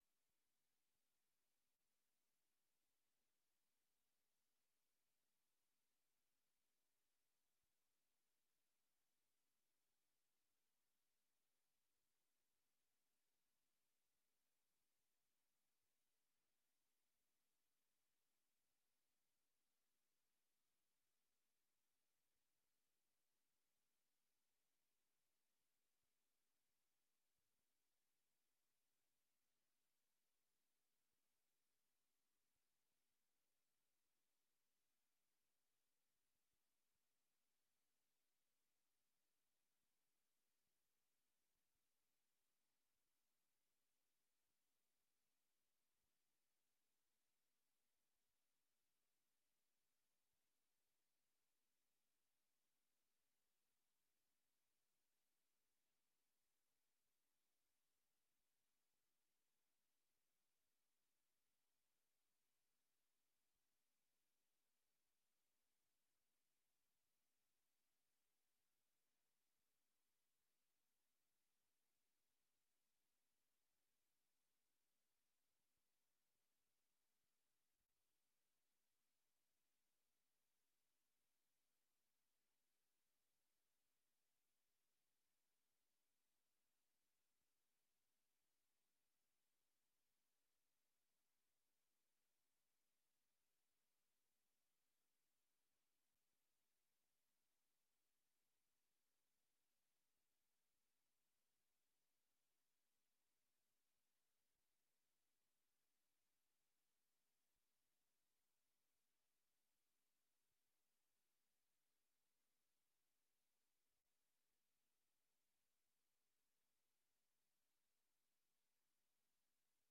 Raadsvergadering 30 maart 2022 19:30:00, Gemeente Dronten
Locatie: Raadzaal